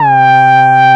ARP LEAD 1.wav